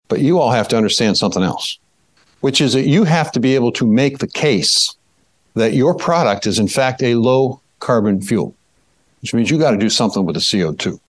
(NATIONAL ASSOCIATION OF FARM BROADCASTING)- US Agriculture Secretary Tom Vilsack told attendees of Growth Energy’s annual Hill Summit (Sept. 11-14, 2023), it’s a “make or break moment” for the biofuels industry when it comes to Sustainable Aviation Fuel.